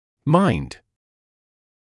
[maɪnd][майнд]ум; разум; мнение; точка зрения; возражать, иметь что-л. против; быть внимательным